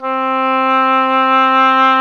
WND OBOE2 00.wav